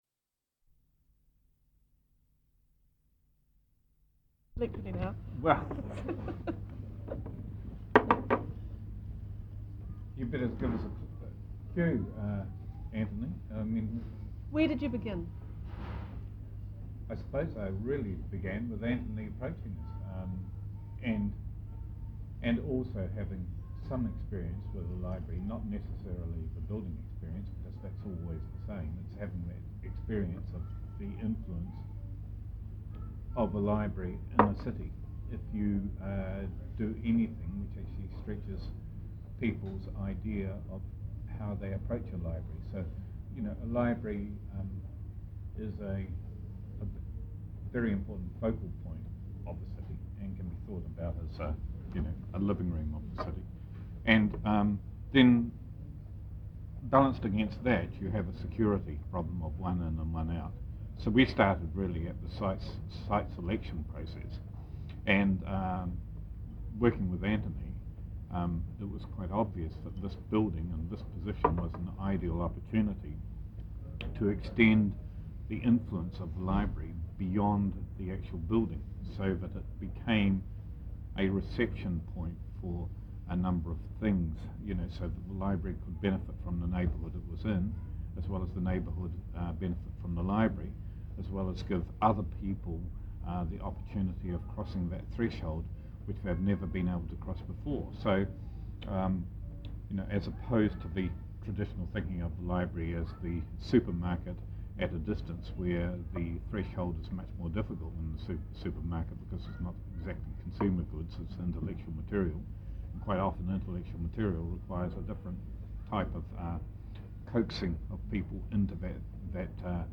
Oral Interview - Ian Athfield - Manawatū Heritage